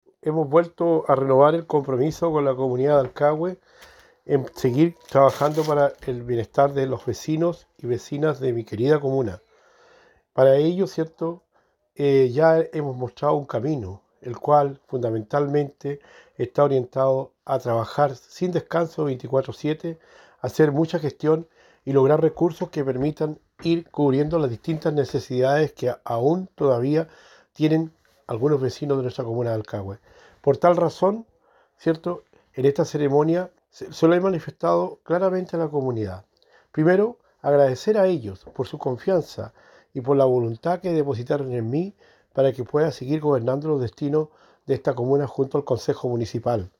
También en Dalcahue, el alcalde Juan Hijerra, recalcó la importancia de renovar el compromiso con la comunidad.